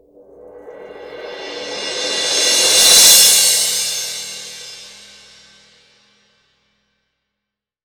Index of /90_sSampleCDs/AKAI S6000 CD-ROM - Volume 3/Crash_Cymbal2/MALLET_CYMBAL